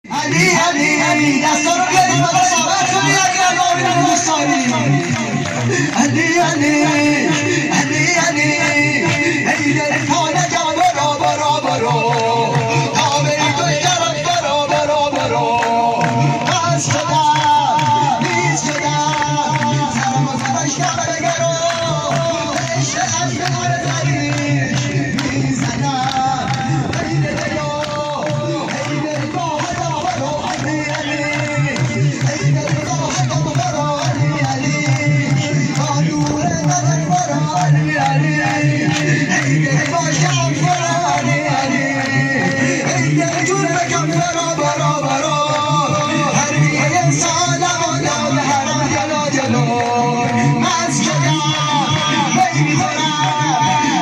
قائم آل محمد راوه - سرود عید غدیر